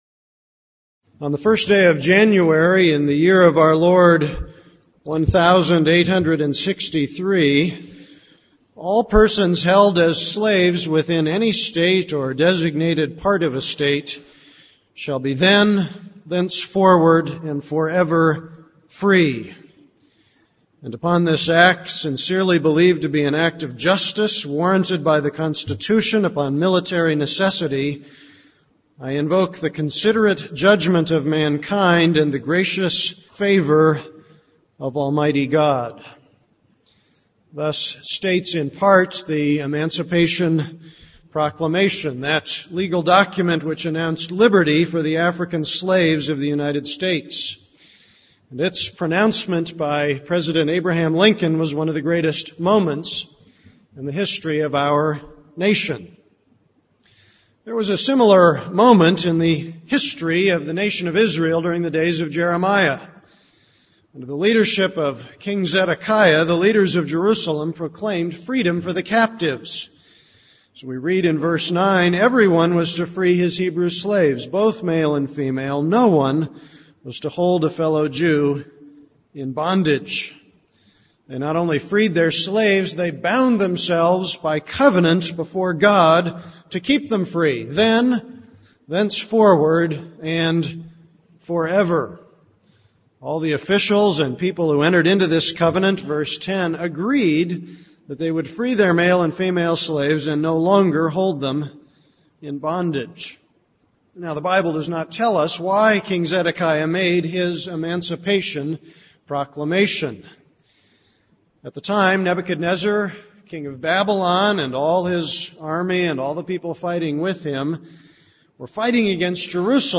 This is a sermon on Jeremiah 34:1-22.